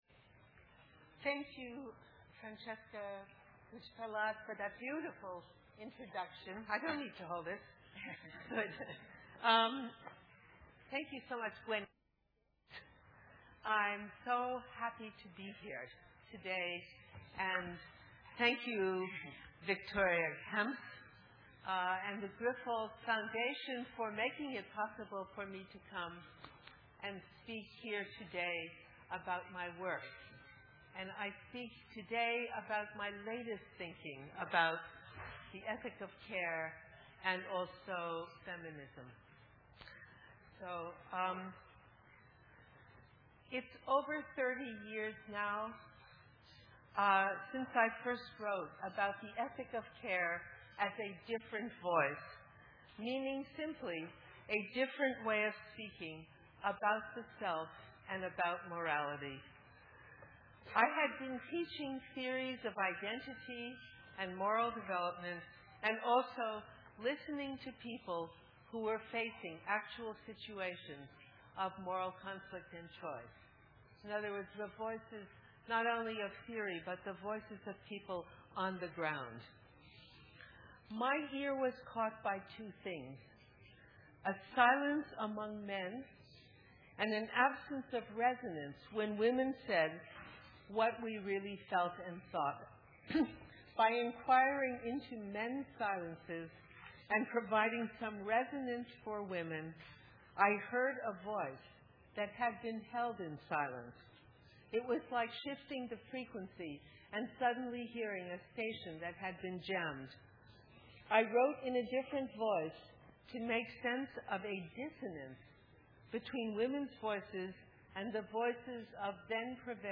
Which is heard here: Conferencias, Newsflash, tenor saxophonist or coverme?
Conferencias